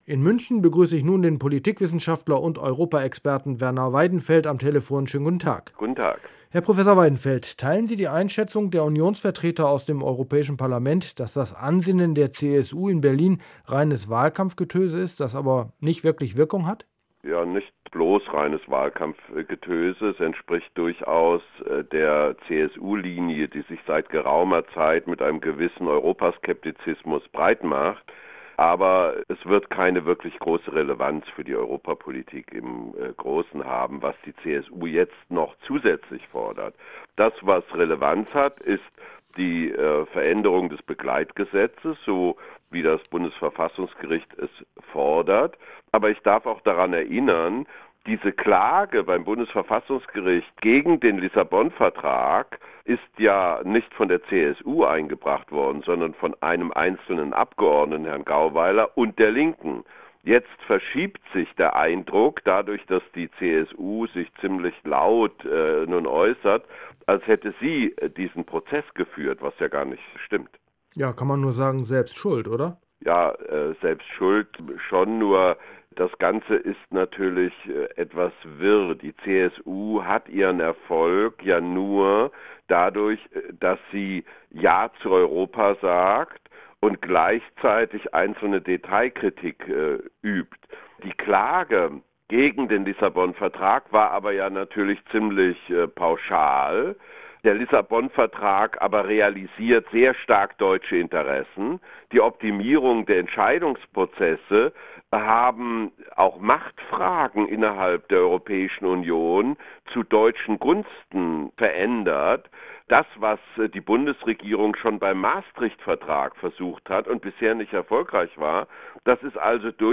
Erste Lesung des Begleitgesetzes zum Lissabonvertrag - Interview mit Werner Weidenfeld